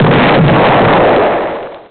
snd_badexplosion.wav